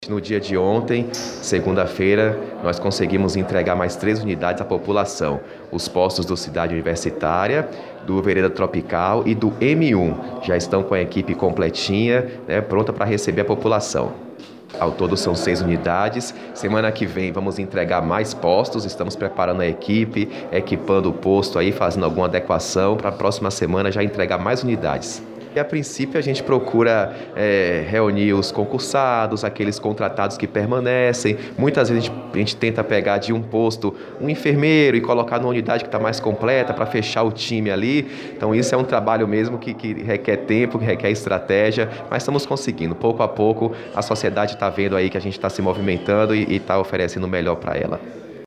OUÇA SONORA DO SECRETÁRIO DE SAÚDE, DARKSON MARQUES